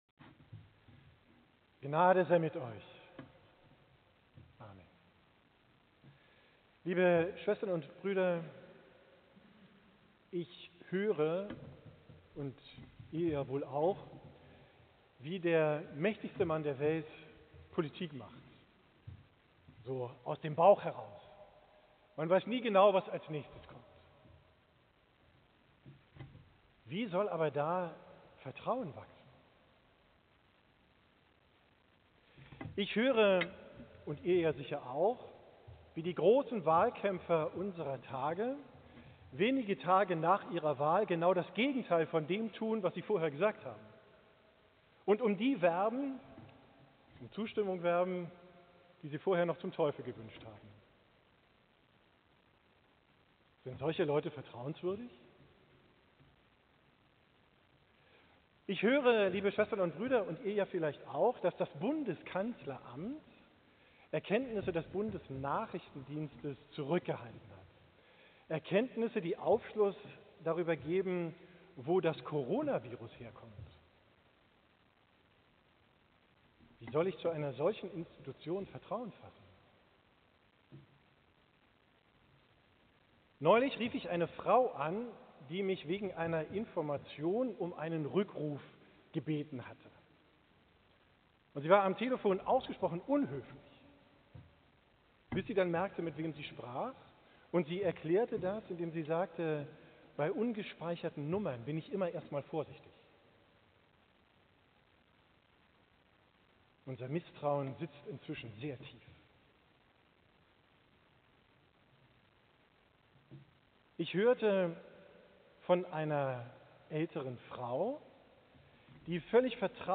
Predigt bom Sonntag Reminszere, 16.